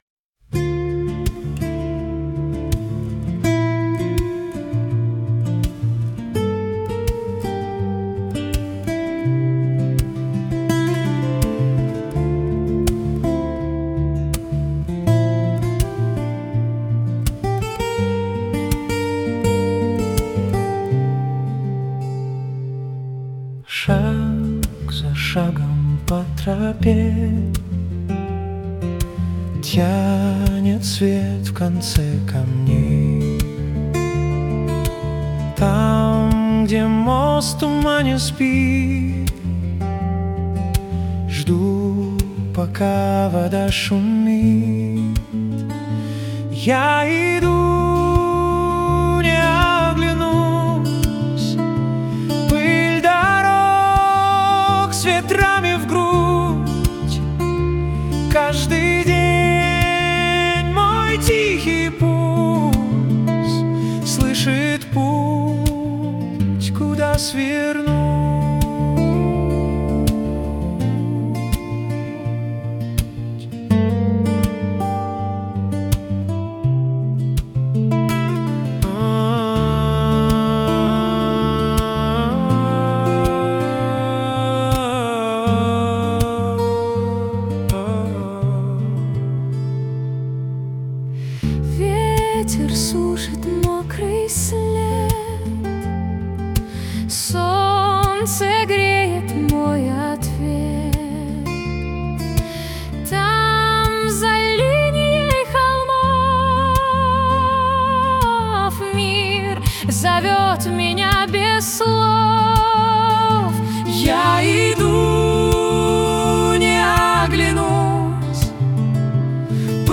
Лирическая акустическая баллада